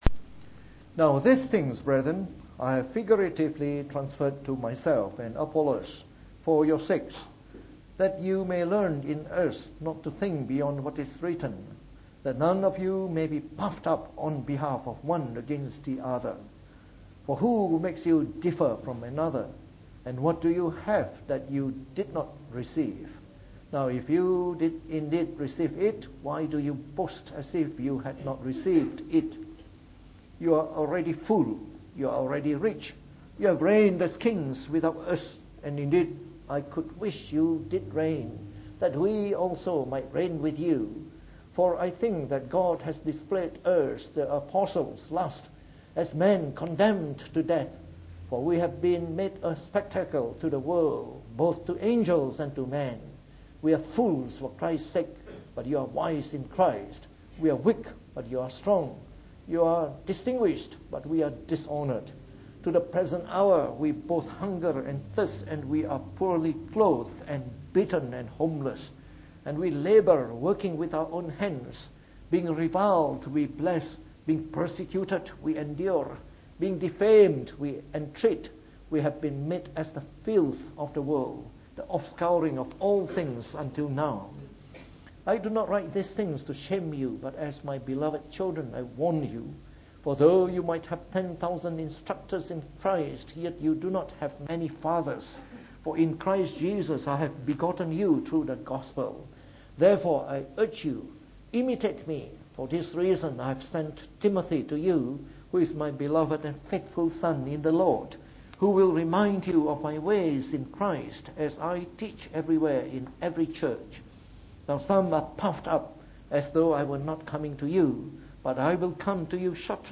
From our series on 1 Corinthians delivered in the Evening Service.